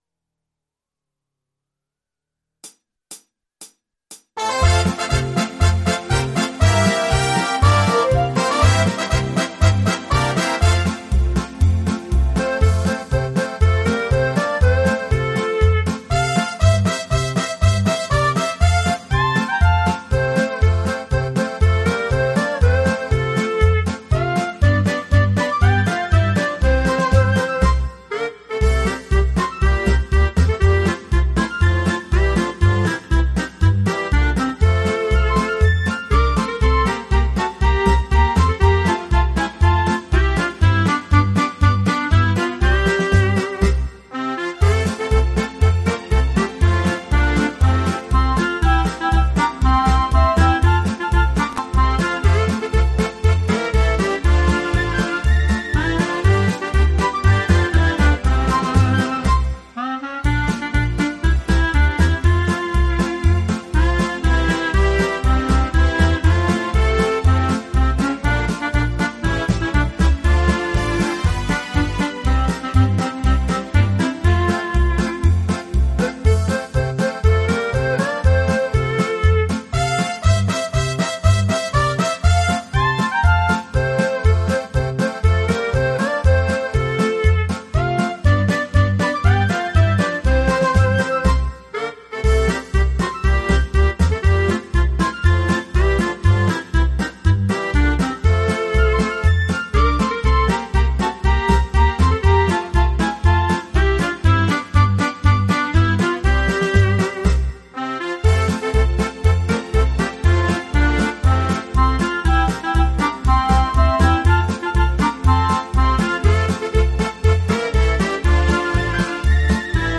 mehrspurige Instrumentalversion